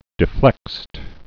(dĭ-flĕkst, dēflĕkst)